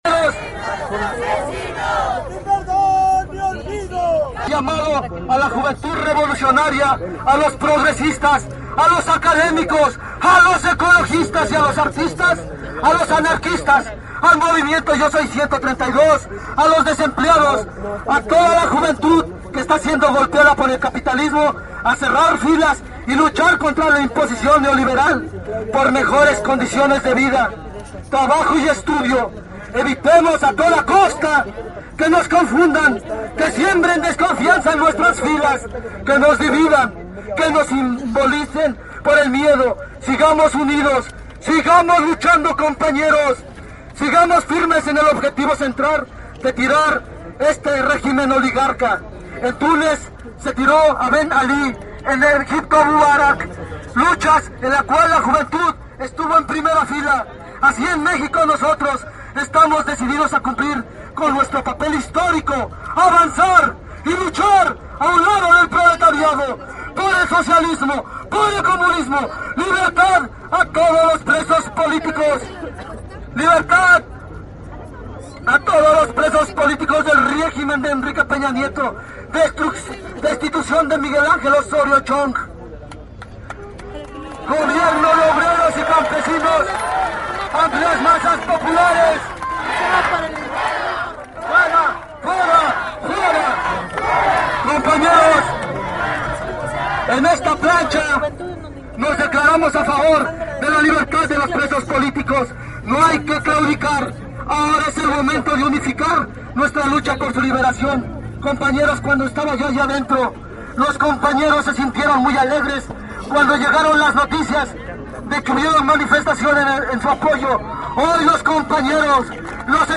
Durante el mitin en el zócalo se vivía un ambiente de tranquilidad, silencio y atención a las palabras emitidas a través del megáfono.Los presentes escuchaban con atención sentados desde sus lugares en la plancha del Zócalo.